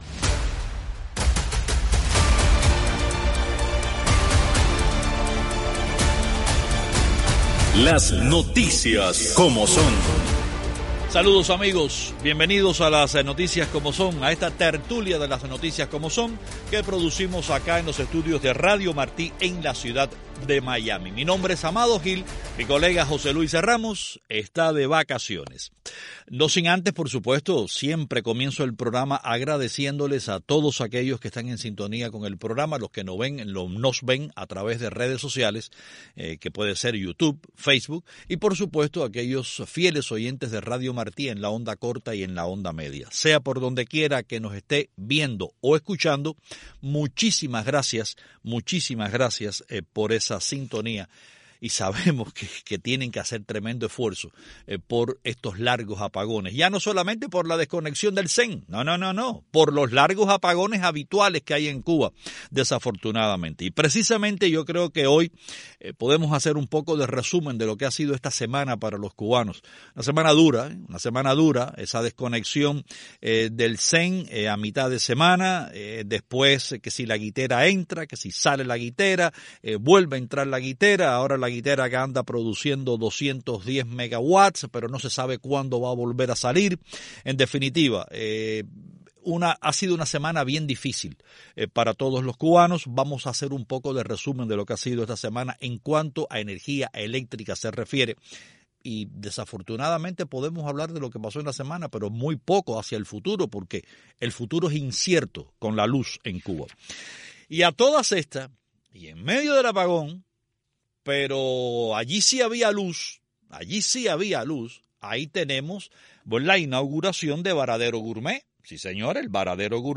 El gobierno cubano desató una oleada represiva tras las protestas del 11 de julio en Cuba. Madres de las víctimas y madres arrestadas en el contexto de las manifestaciones, dan testimonios de violaciones a los derechos humanos y judiciales en Cuba.